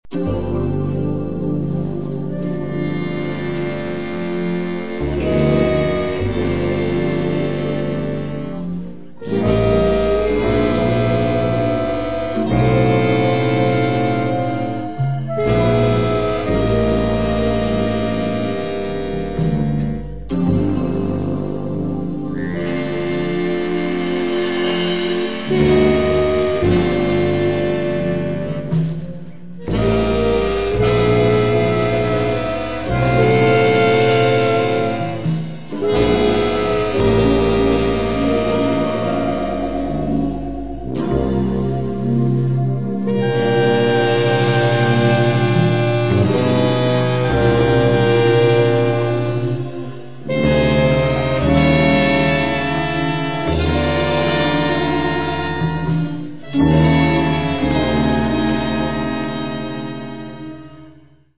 tromba e flicorno
sax, clarinetto basso
trombone, tuba
bombardino
chitarra
contrabbasso
batteria